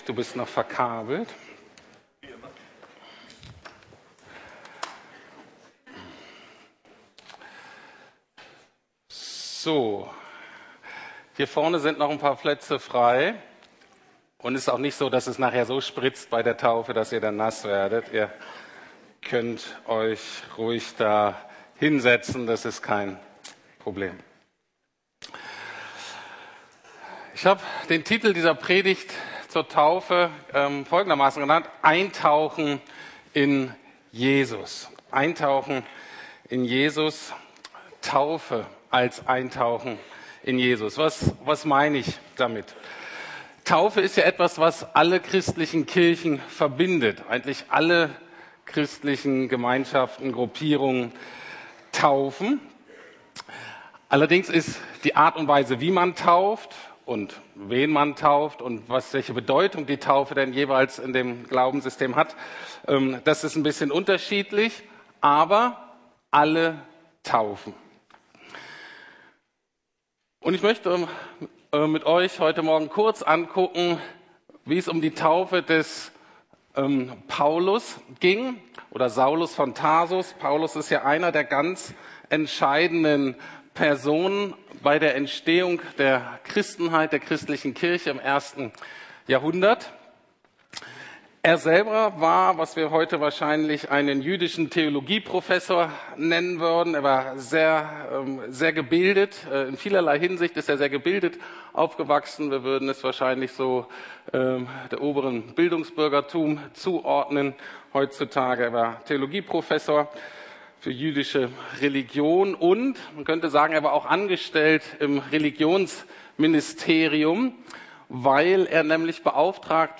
Taufe - Eintauchen in Jesus ~ Predigten der LUKAS GEMEINDE Podcast